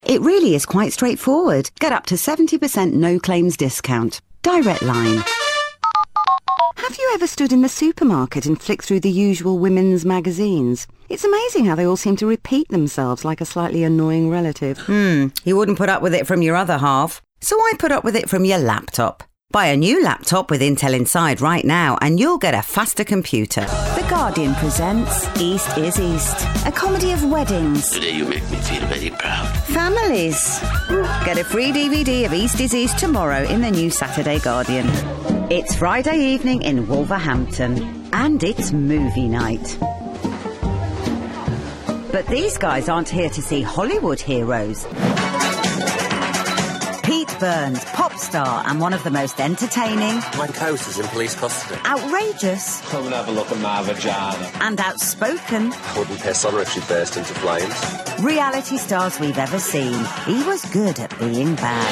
Main Showreel
Straight
Showreel, Bright, Light, Energy